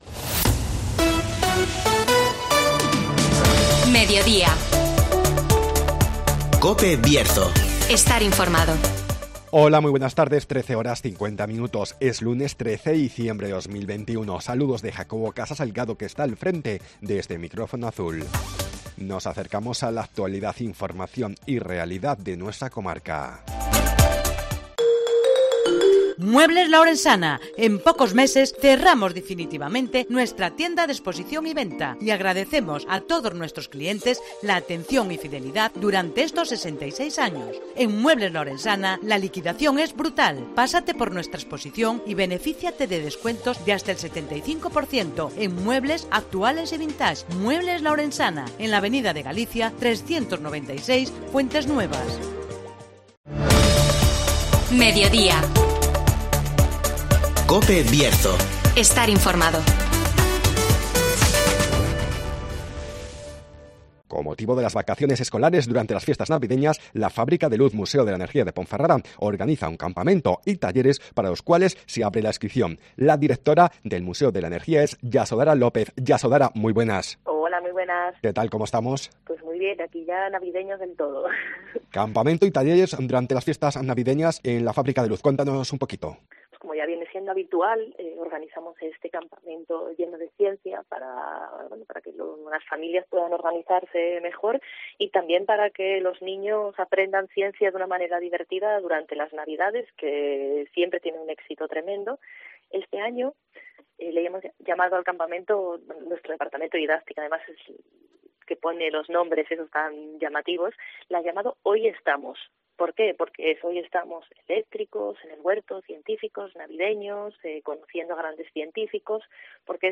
ACTUALIDAD
Entrevista